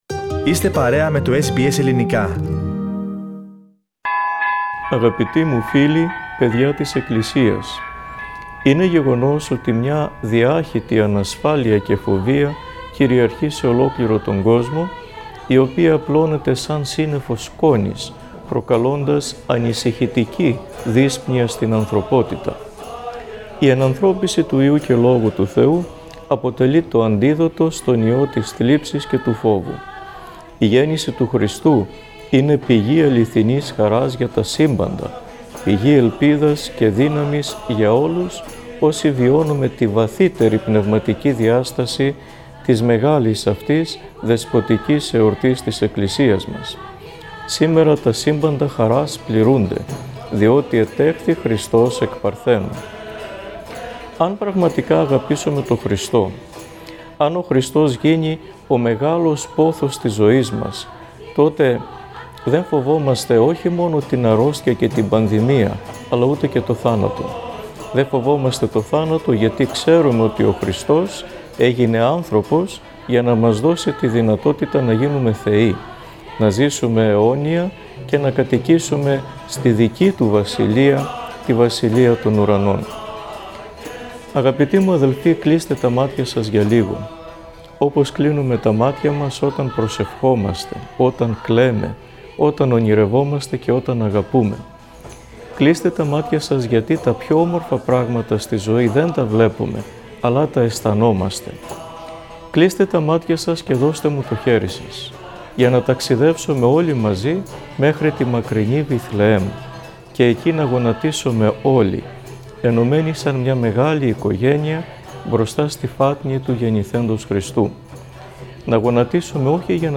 Επικαρικό χαρακτήρα έχει το φετινό μήνυμα του Αρχιεπισκόπου Αυστραλίας για τη γιορτή των Χριστουγέννων.